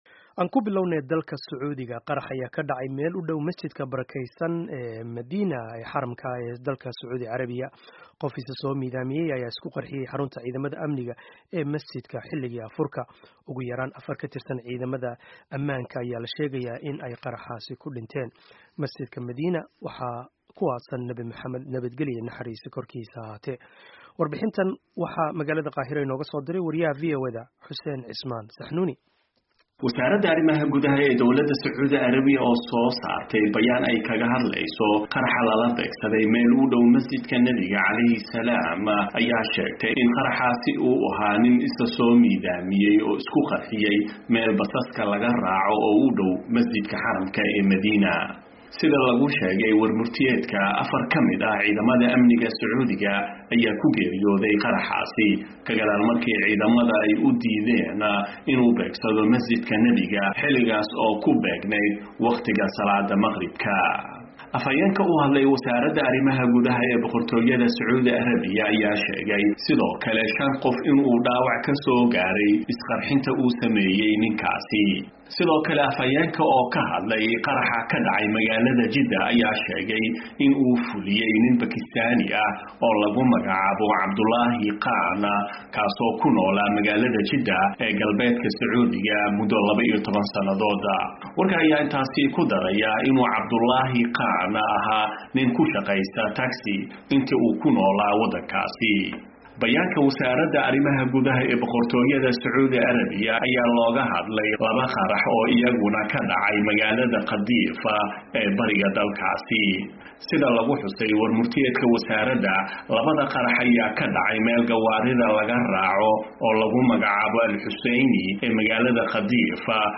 Warbixin: Qaraxa Masjidka Xaramka Madiina